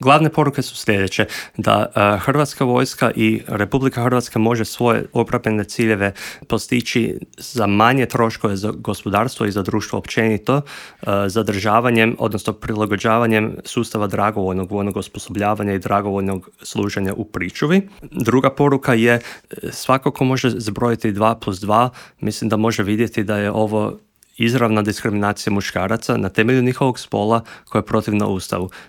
Intervjuu Media servisa